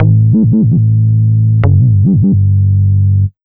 AMB147BASS-L.wav